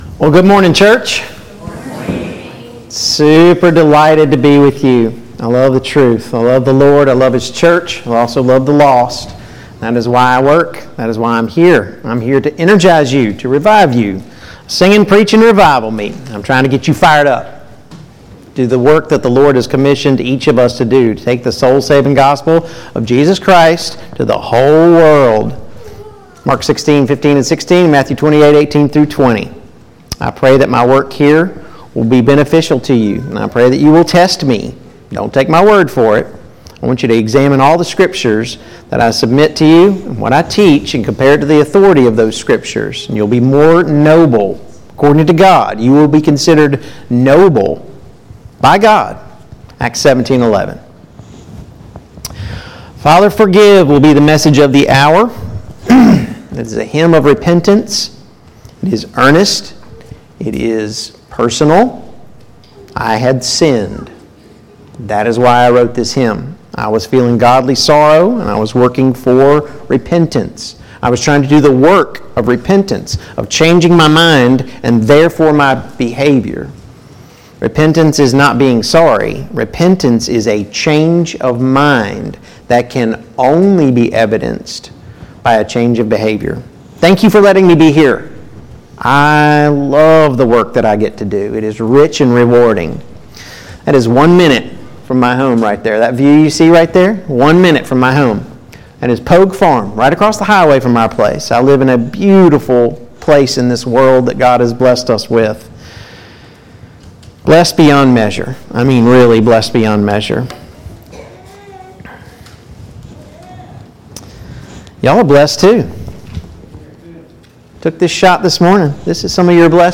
Series: 2022 Spring Gospel Meeting Service Type: Gospel Meeting Download Files Notes « 1.